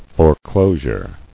[fore·clo·sure]